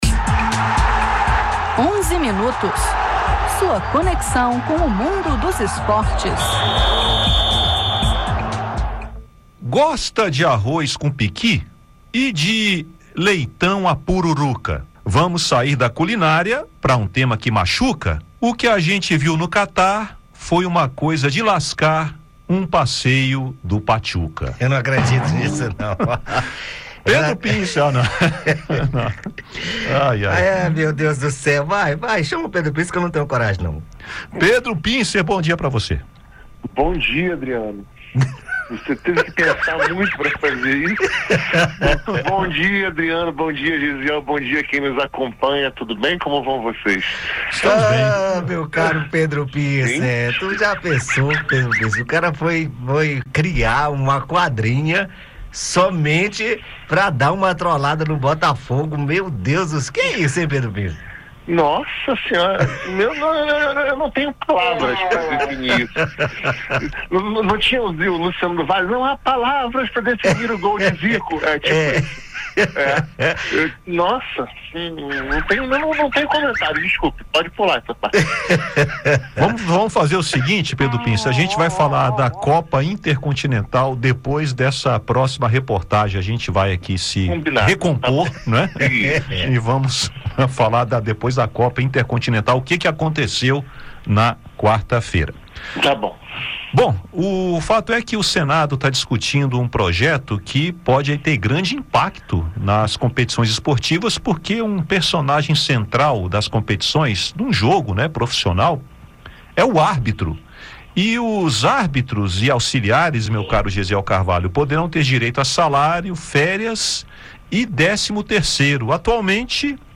Acompanhe também comentários da Copa Intercontinental, tabela do Mundial de 2027, copas de 2030 e 2034 e morte de Amaury Pasos.